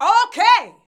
O.K.     2.wav